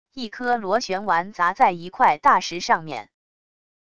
一颗螺旋丸砸在一块大石上面wav音频